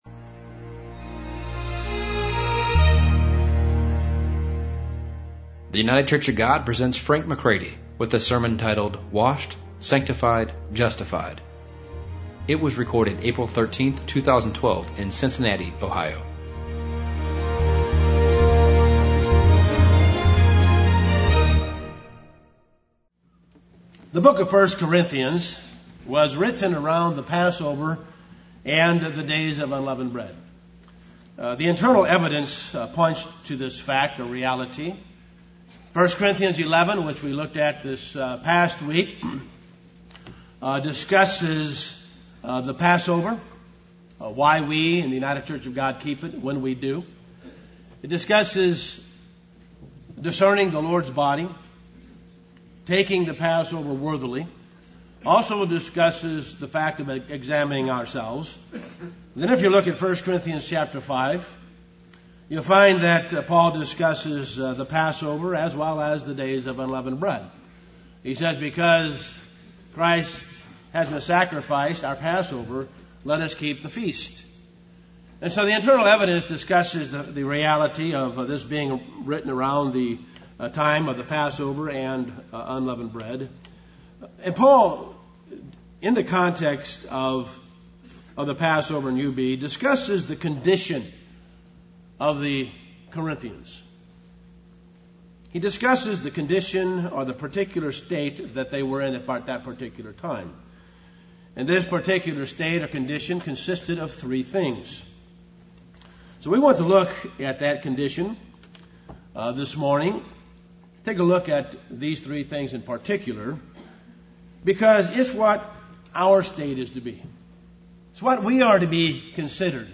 [This sermon was given on the last day during the Days of Unleavened Bread.]